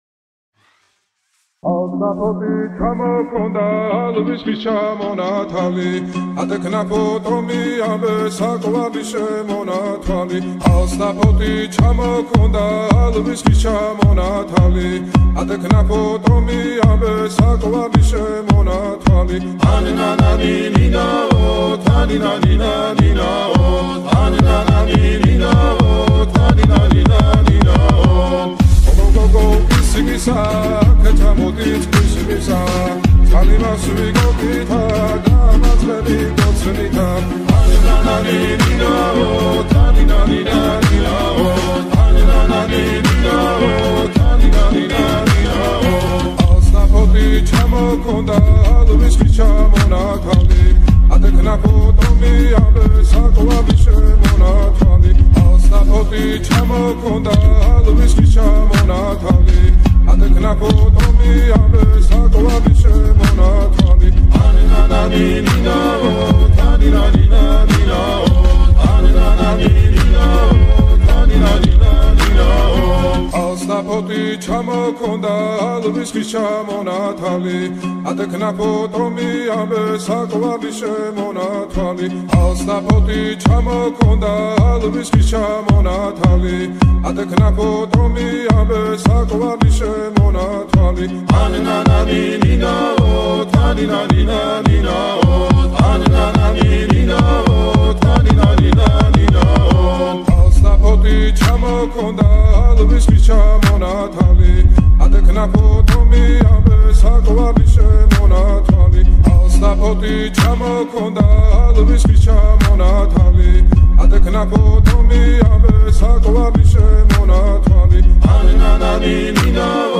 Грузинский Фолк Ачарули гандаган трэп ремикс